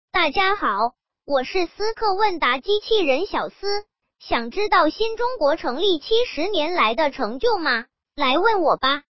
语音支持：苏州思必驰信息科技有限公司